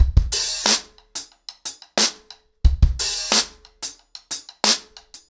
ROOTS-90BPM.35.wav